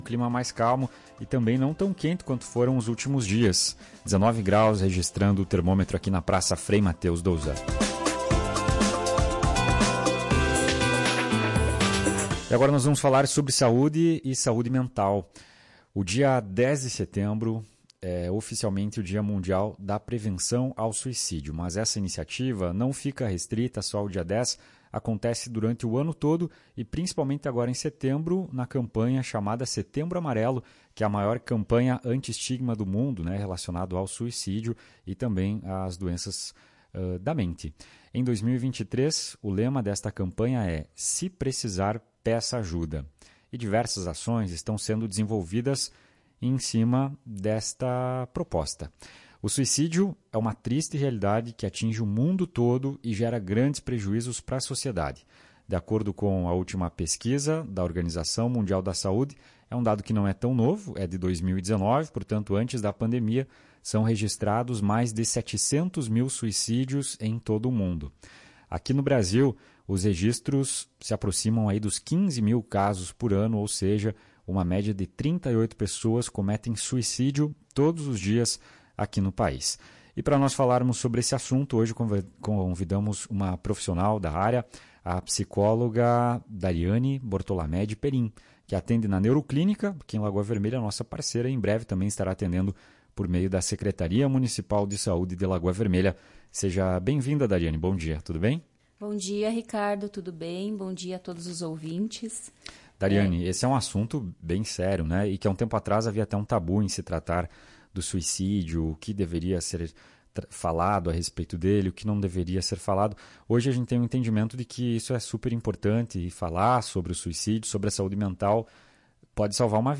durante entrevista Foto